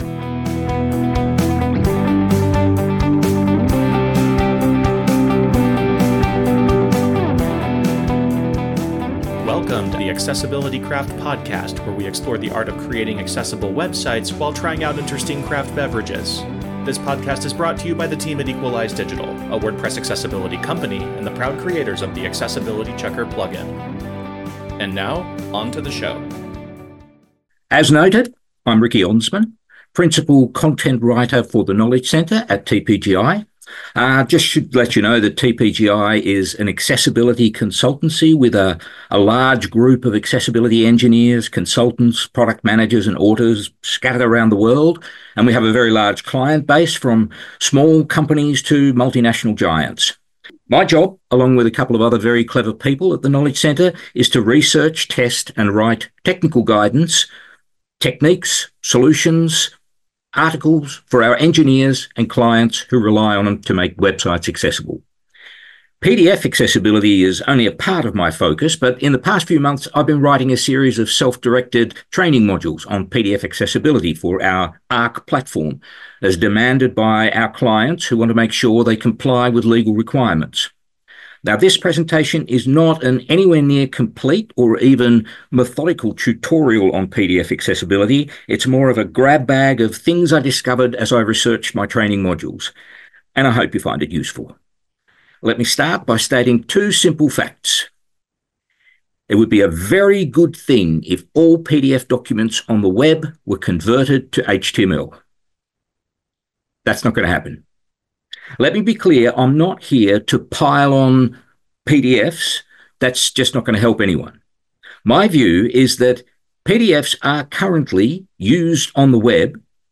WordPress Accessibility Meetups take place via Zoom webinars twice a month, and anyone can attend.